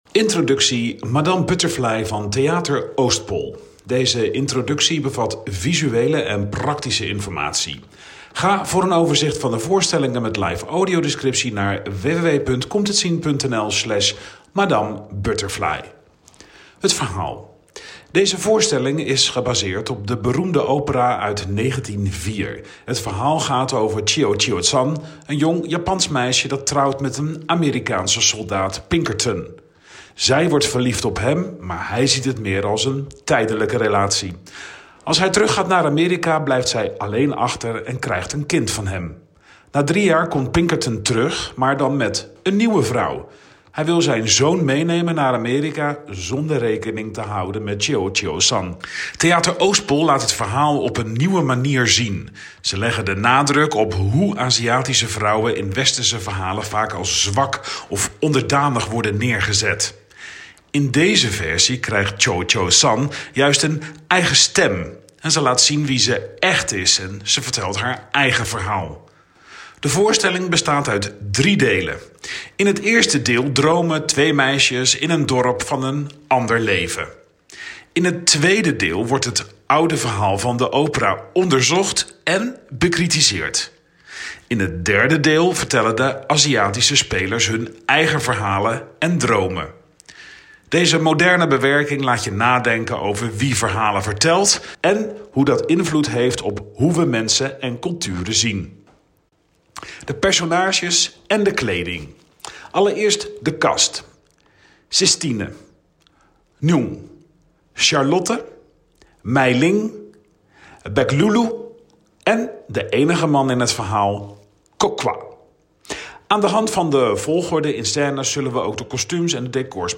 Audiodescriptie door blindentolken, live voor theater, evenementen, festivals en uitjes